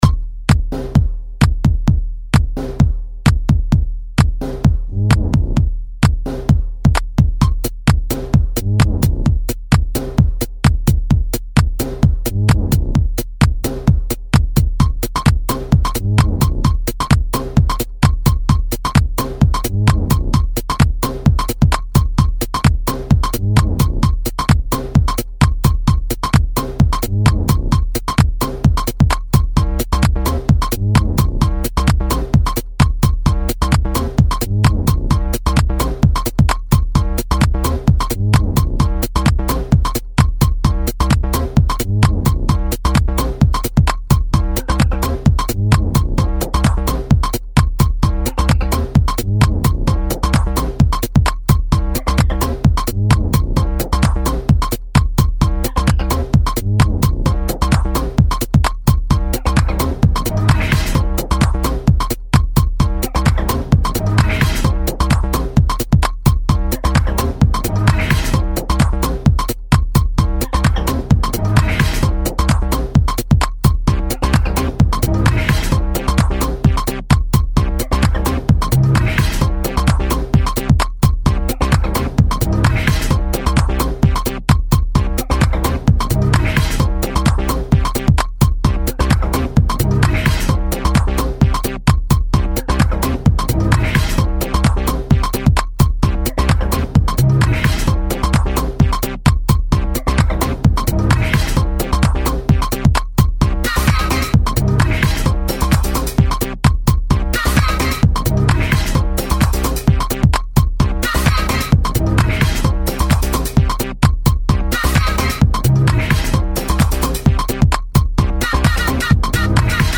Filed under bangers, disco
Filed under bangers, blabla